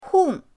hong4.mp3